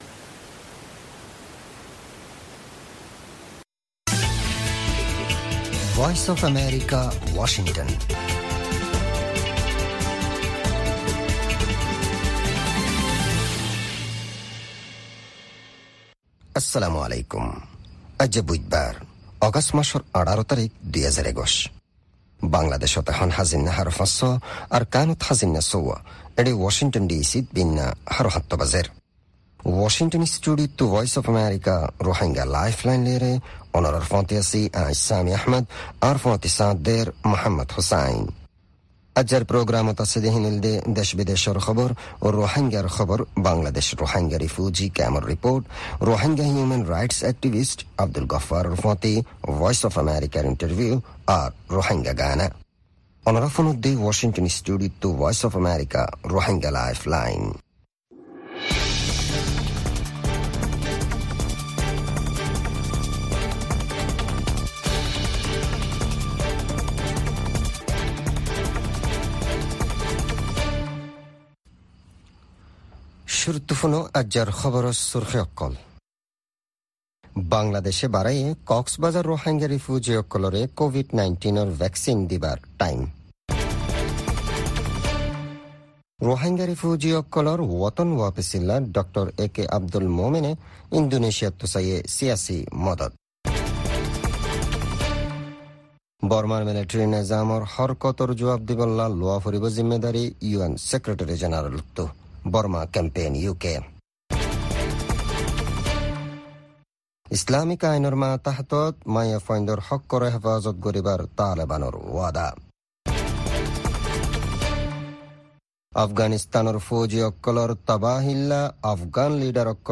Rohingya “Lifeline” radio - Wednesday, August 18, 2021